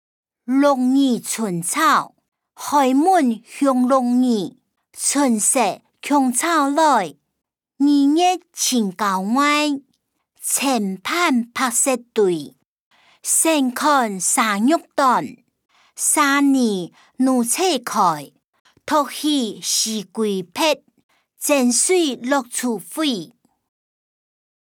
古典詩-鹿耳春潮音檔(饒平腔)